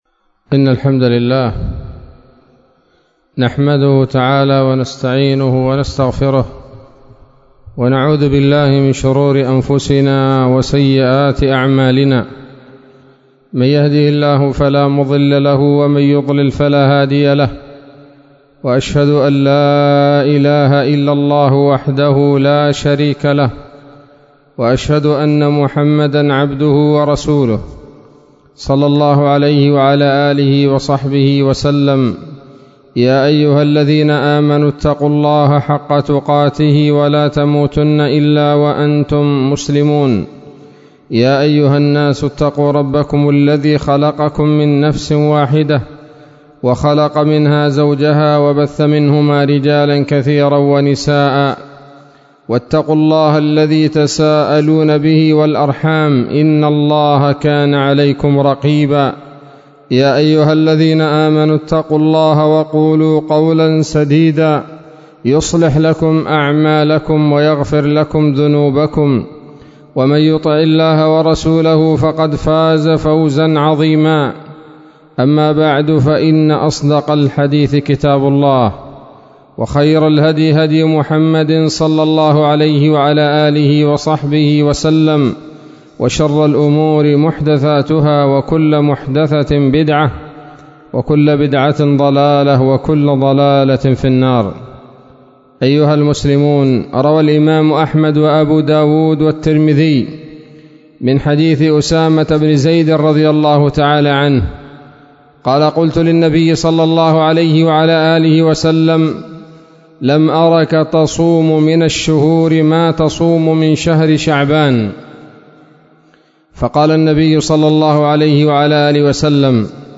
خطبة جمعة بعنوان: (( وظائف شهر شعبان )) 15 شعبان 1443 هـ، دار الحديث السلفية بصلاح الدين